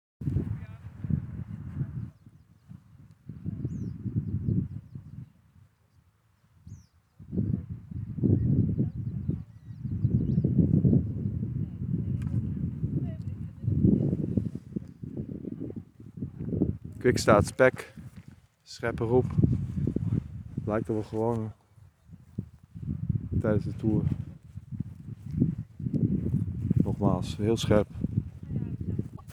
Deze vogel produceerde een roep die ons allebei in de alarmstand zette, want deze leek waanzinnig veel op Roodkeelpieper. Het is een roep die ik nog nooit heb gehoord van een Gele kwikstaart, het was ook geen tussenroepje (het was het enige geluid dat de vogel voortbracht) en een bericht in verschillende appgroepen leverde ook eensluidend de reactie op dat dit wel erg afwijkend was.
Dit was de vogel die het geluid voortbracht.